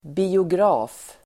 Uttal: [bi:ogr'a:f]